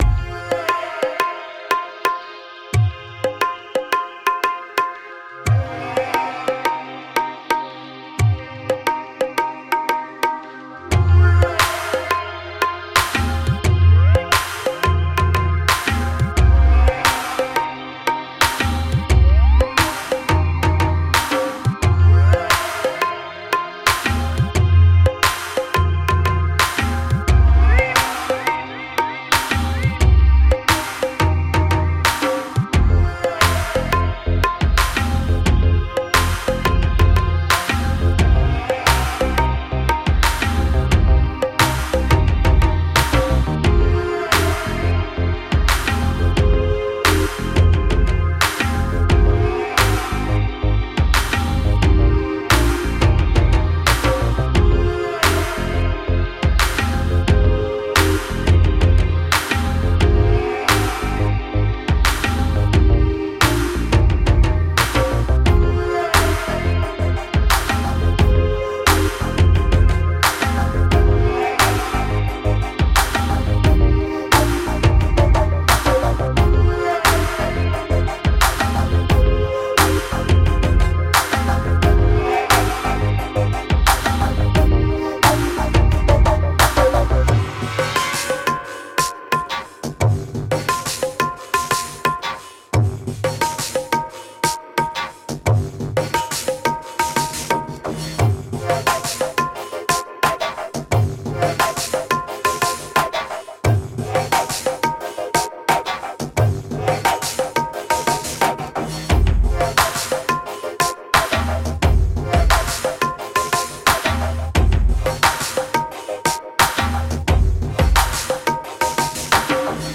A world of independent music with electronic spice.
A mix of world music and contemporary electronica.
Tagged as: World, Folk